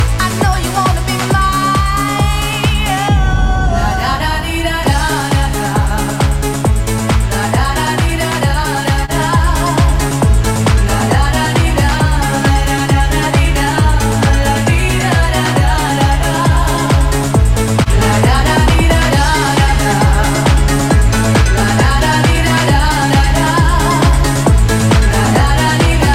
from small to big